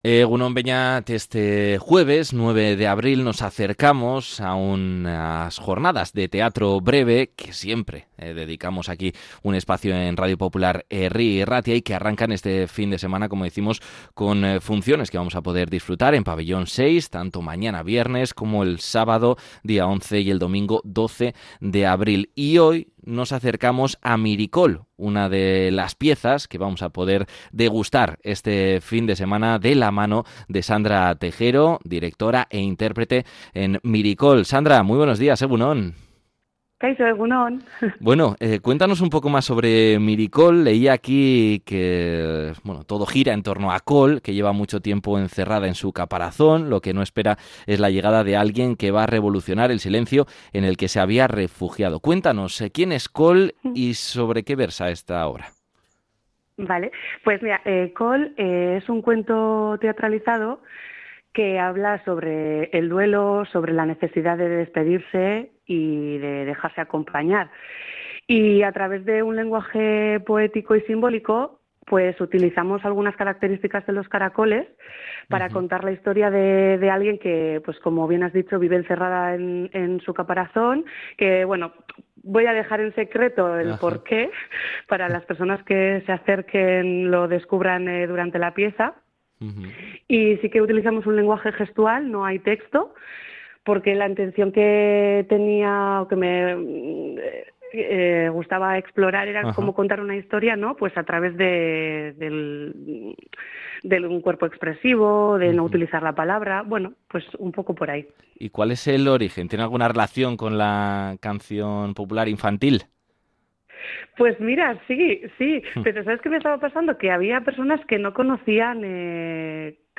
ha pasado por el programa EgunOn Bizkaia de Radio Popular – Herri Irratia para presentar ‘Miricol‘